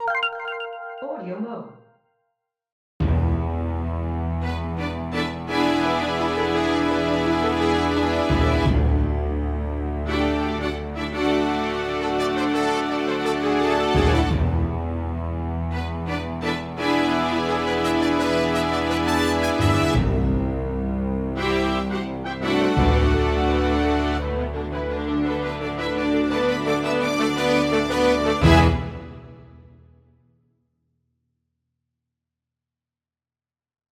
It tries to sound like some epic intro that you might get while introducing a film or major character, with just two patches. One is a combi which has a bass drum and some low brass and maybe piano that I use to mark my current note and root of the chord, as well as a top of a full string section. The other is some kind of brass thing which does most of the melodic stuff for the majority of the jingle, but turns into a build detail at the end.